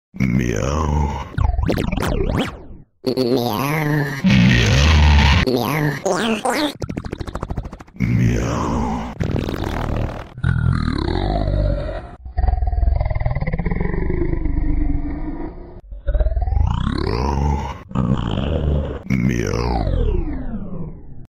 Meow Man MeMe Sound Variations sound effects free download
Meow Man MeMe Sound Variations sound effect